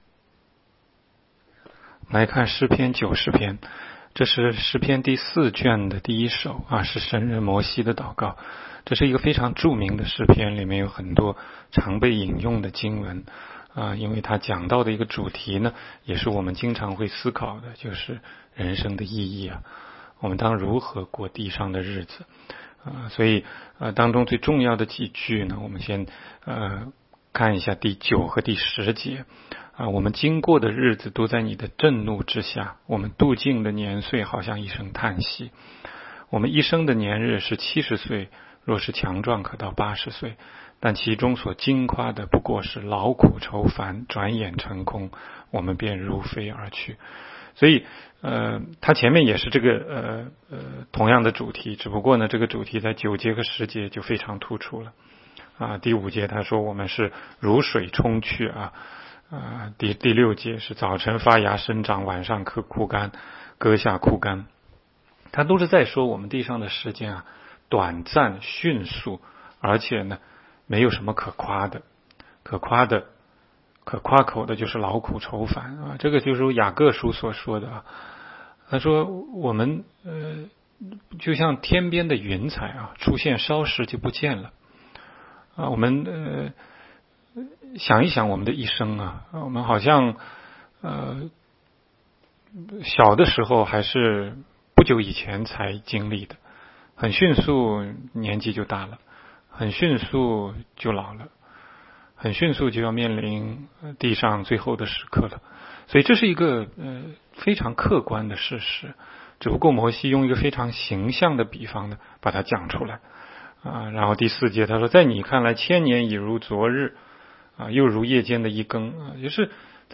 16街讲道录音 - 每日读经-《诗篇》90章